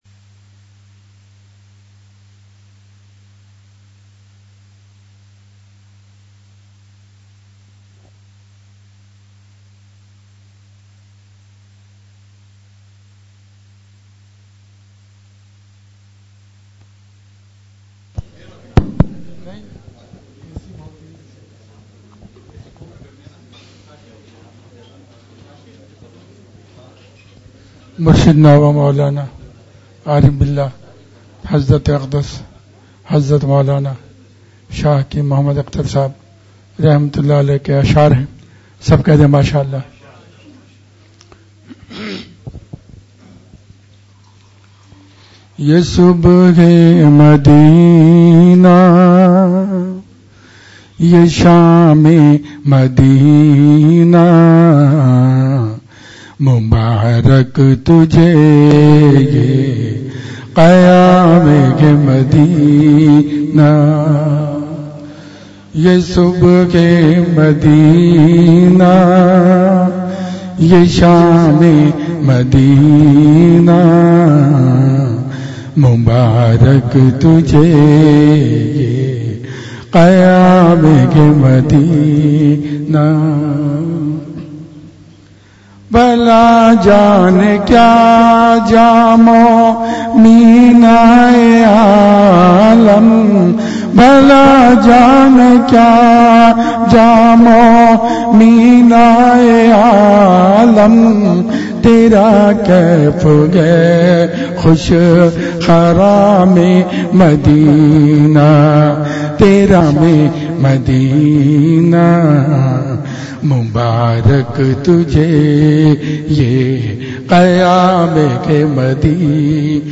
اصلاحی مجلس